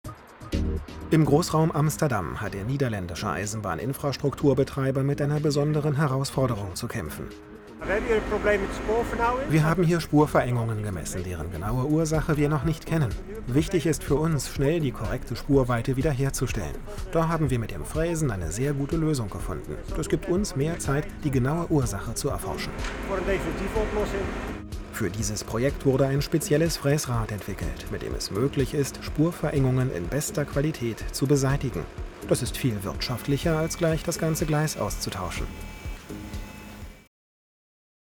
Der große Sprecherraum-Mikrofonvergleich
Hier meine Meinung: Find ich insgesamt gut vom Sound.
Deine Stimme näselt etwas.
Die sind schon nah dran, aber 1-2 db dürfen da noch de-esst werden Habs mal kurz über Kopfhörer in Reaper bearbeitet. Dadurch wurde natürlich auch der "Backingtrack" mit bearbeitet: